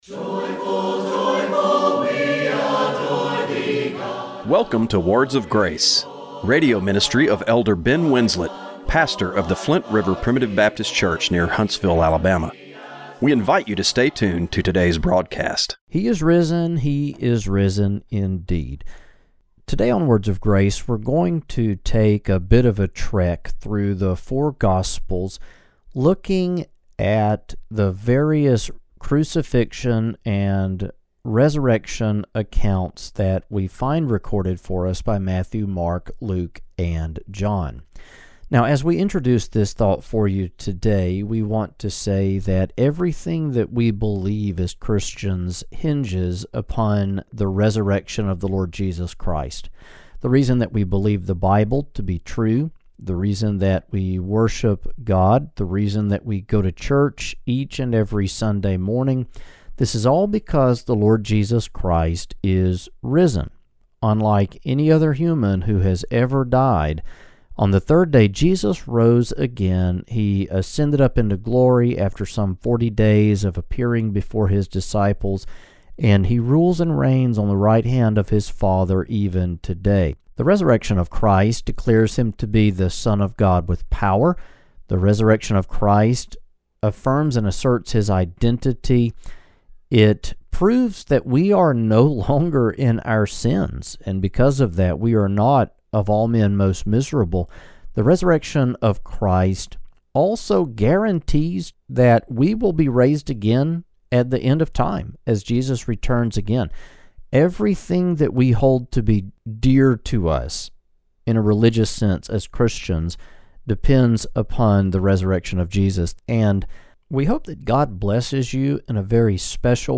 Radio broadcast for April 20, 2025.